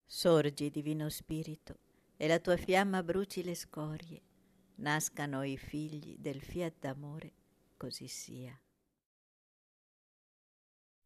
Preghiera mp3